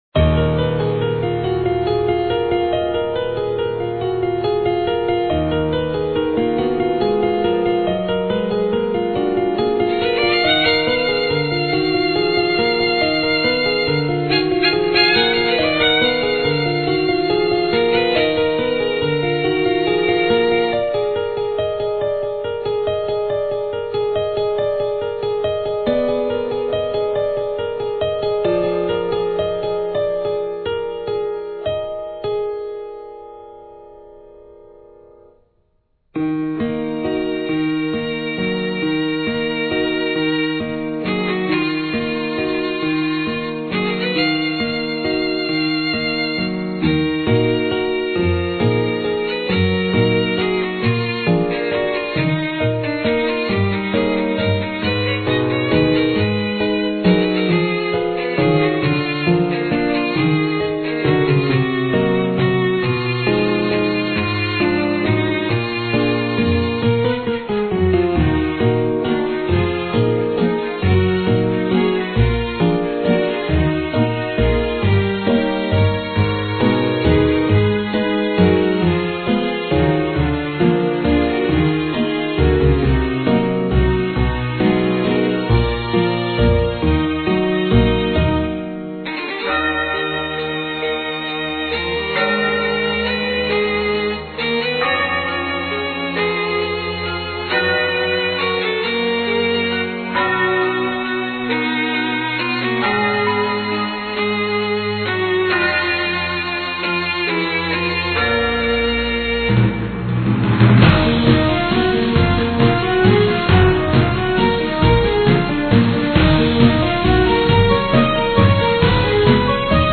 Аналогично номеру восемнадцатому - одна мелодия без голоса.